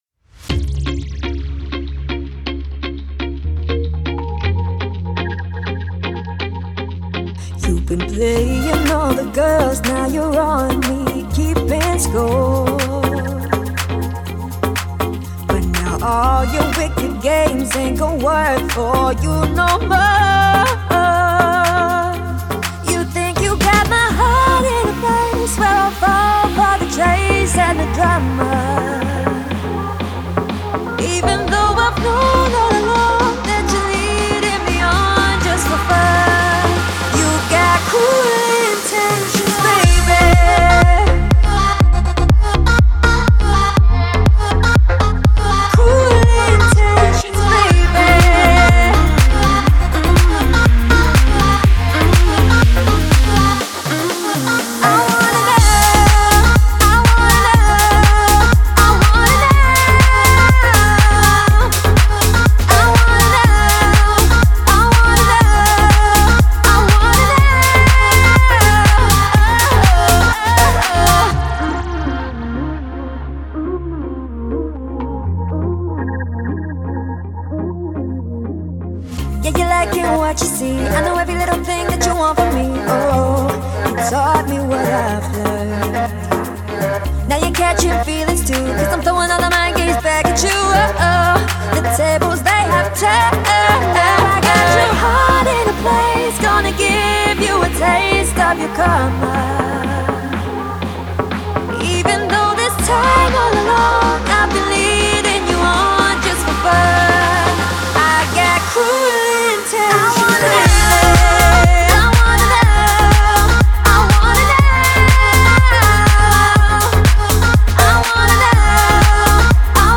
это захватывающая трек в жанре EDM